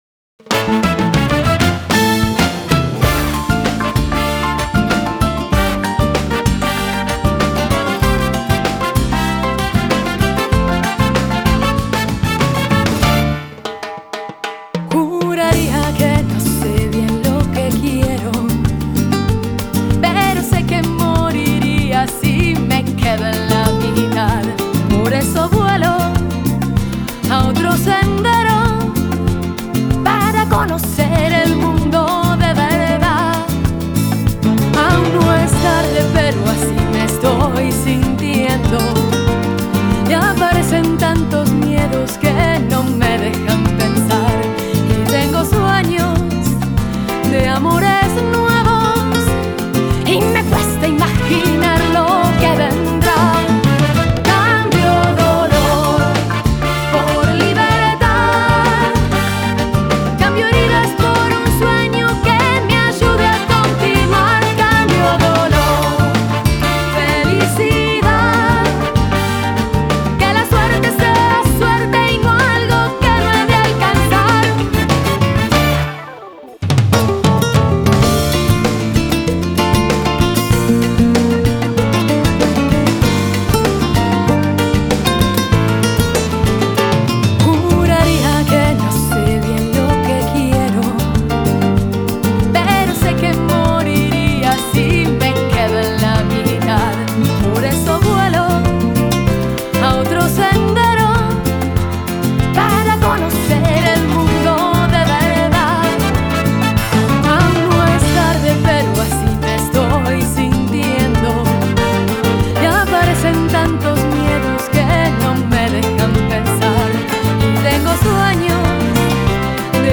певица грузинского происхождения